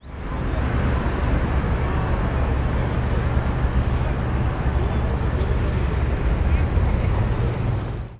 City-Noise-Ambient-4.wav